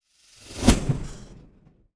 WHOOSH_Steam_Fast_01_mono.wav